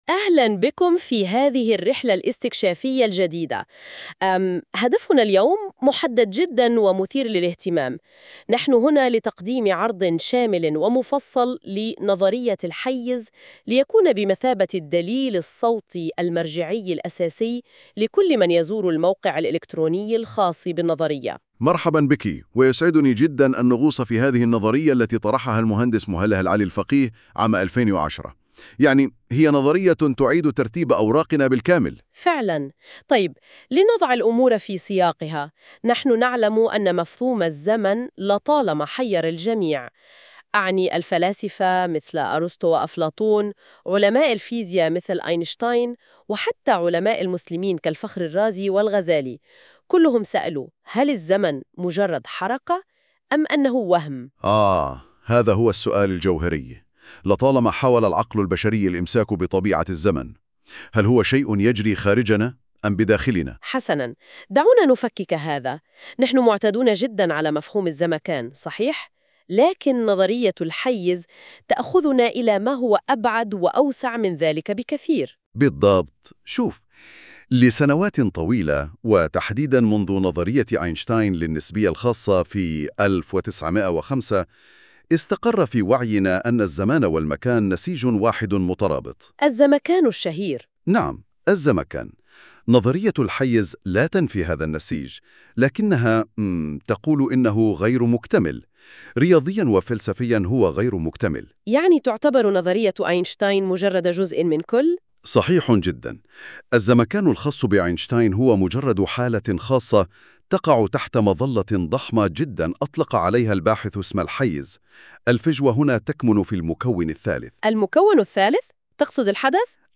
نقاش صوتي يوضح النظرية ويشرح فكرتها في مسار سمعي موازٍ للقراءة.